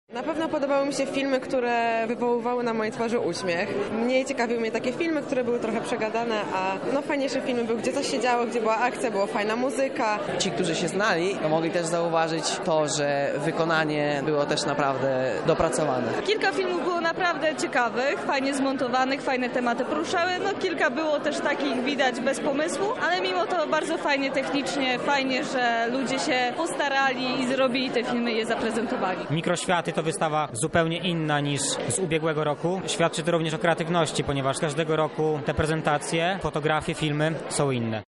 O swoich wrażeniach opowiedzieli uczestnicy wydarzenia.